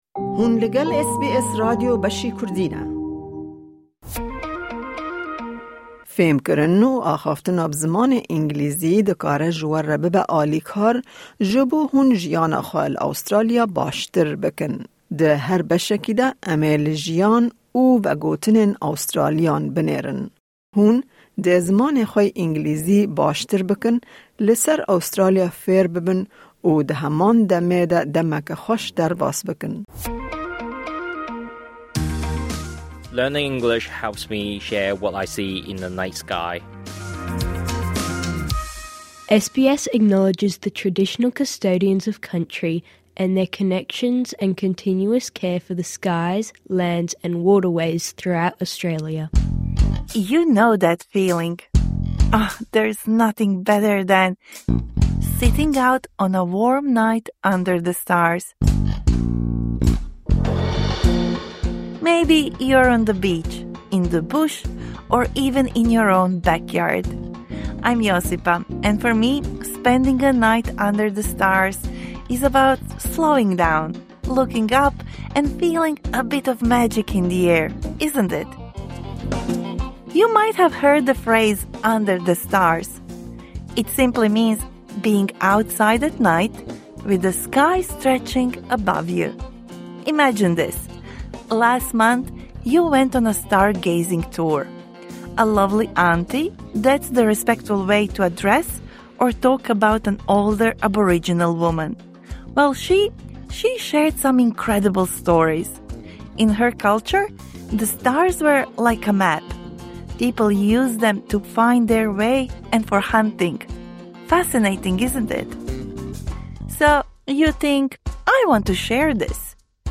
Ev ders ji şagirtên navîn re baş e. Piştî guhdarîkirinê, zanîna xwe bi kiwîza me biceribîne.